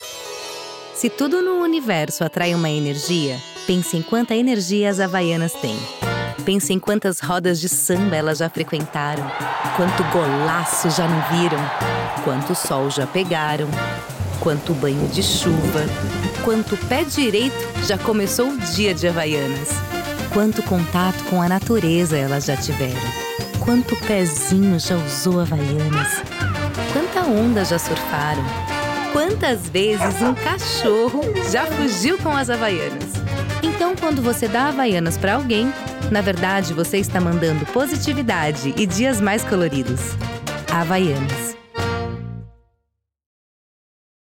Sprechprobe: Werbung (Muttersprache):
My vocal personality ranges from a youth, which inspires lightness, energy and friendliness, to a “maturity”, which conveys confidence, calm and drama. Own studio in the countryside of São Paulo, Brazil. With an excellent acoustic treatment system that offers excellent quality.